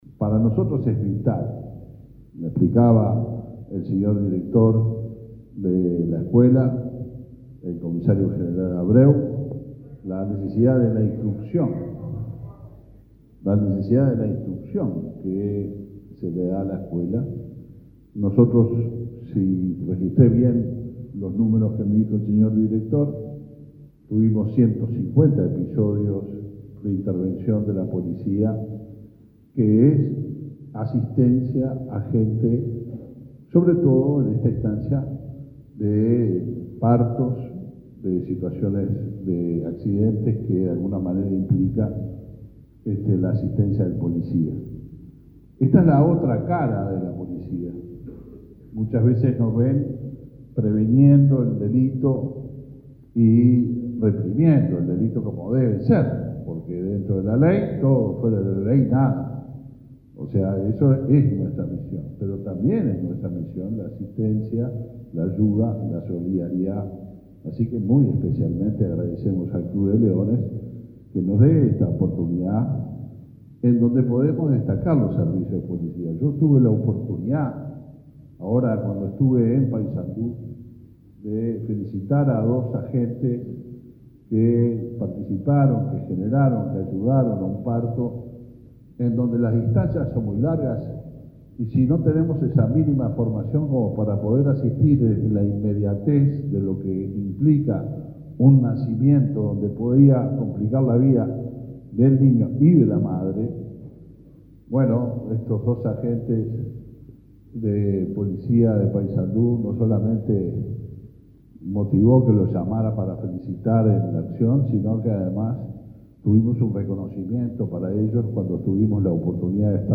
Palabras del ministro del Interior, Luis Alberto Heber
El ministro del Interior, Luis Alberto Heber, participó, este jueves 11 en la Escuela de Policía, de la entrega de una donación del Club de Leones a